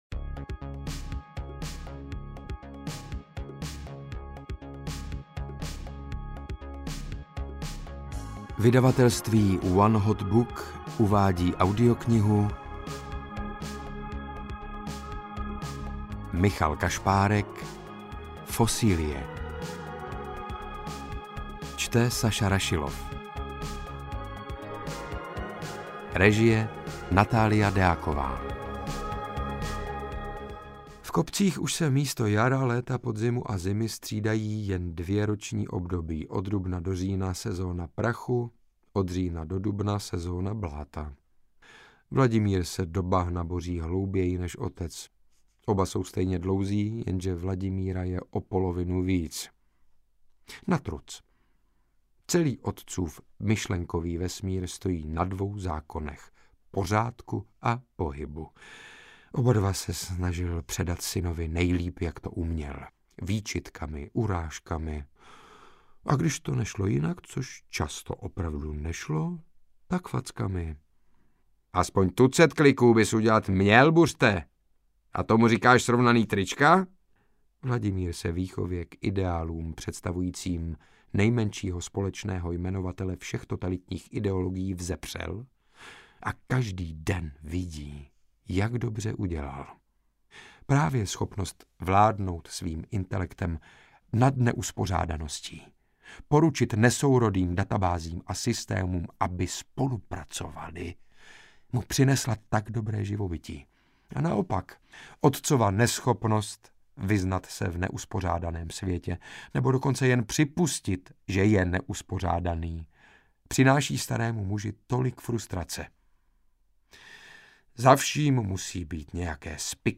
Fosilie audiokniha
Ukázka z knihy
• InterpretSaša Rašilov